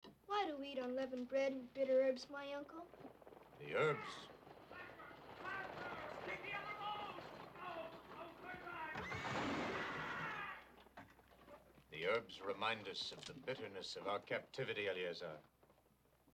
Moses assures her that it will, and then Timmy-Eleazar has a question about the menu, that gets interrupted as the horror and panic noises outside escalate into an honest to goodness chariot crash.
bitternesschariotcrash.mp3